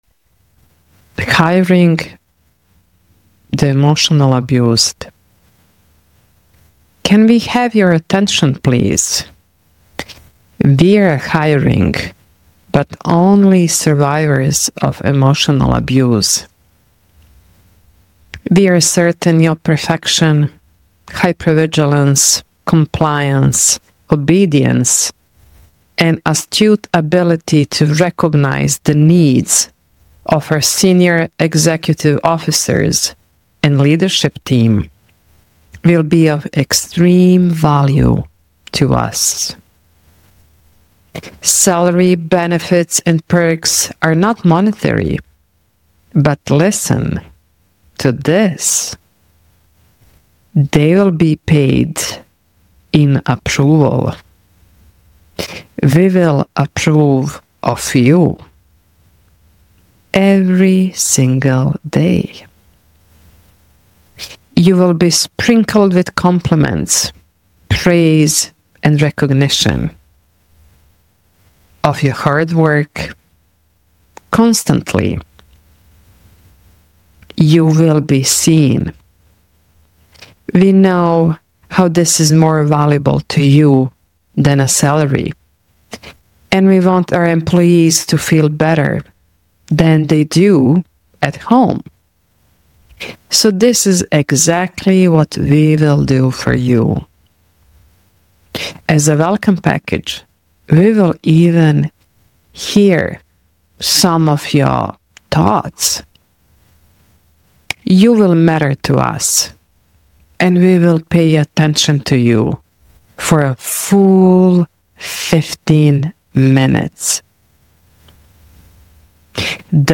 satirical monologue